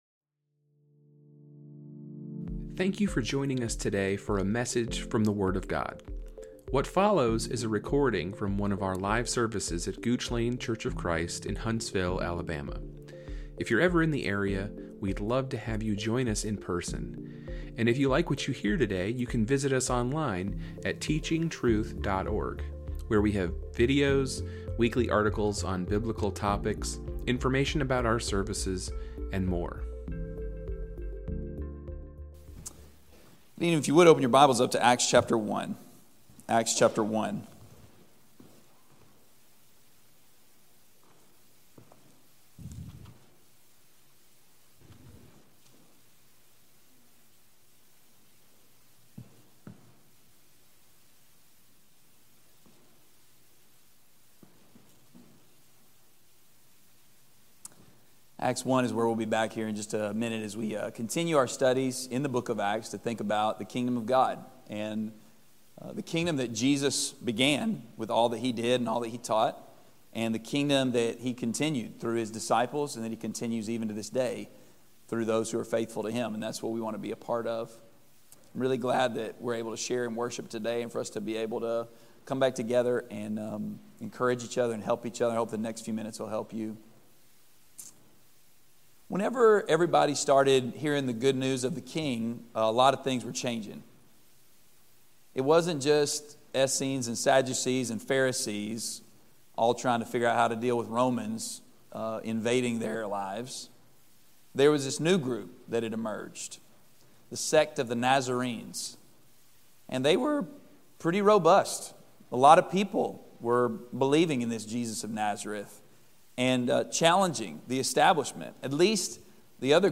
This is the third of eight lessons in the Continuing the Kingdom series
from our gospel meeting in June 2023.